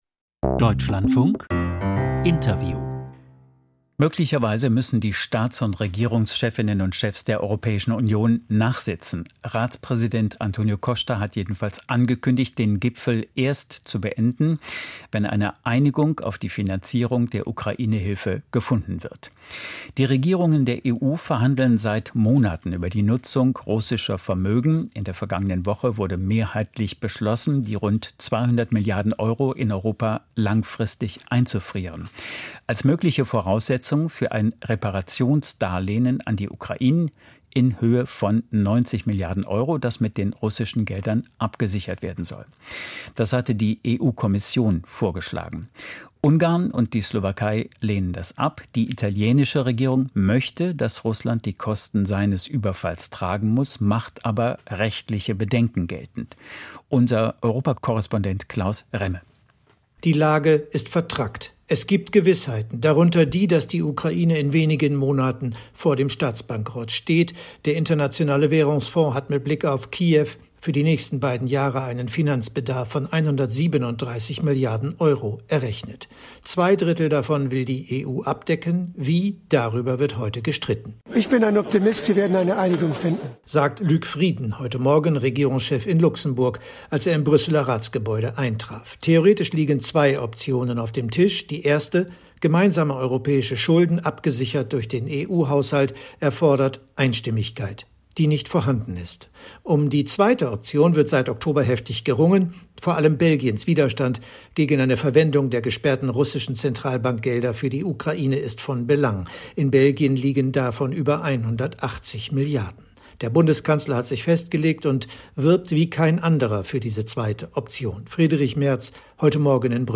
EU-Gipfel - Interview mit Markus Ferber, CSU MdEP